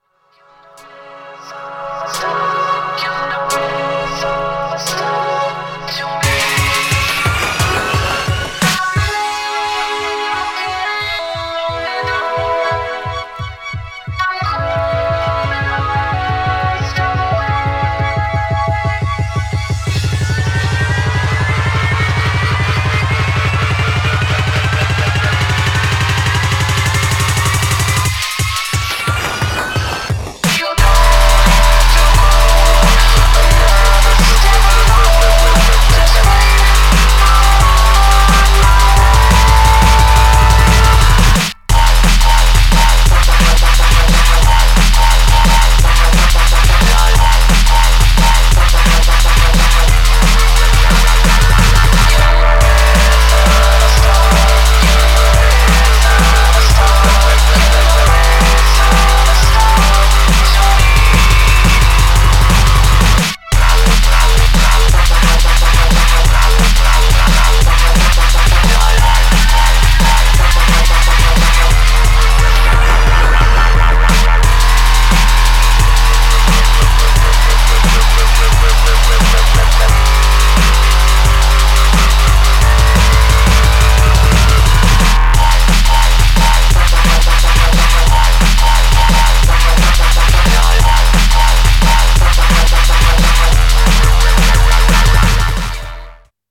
Styl: Dub/Dubstep, Drum'n'bass